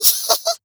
quick_laugh.wav